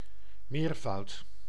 Ääntäminen
Synonyymit multiple several manifold morefold Ääntäminen US : IPA : [ˈplʊɹ.əl] Tuntematon aksentti: IPA : /ˈplʊə.ɹəl/ IPA : /ˈplɔː.ɹəl/ Lyhenteet ja supistumat pl. pl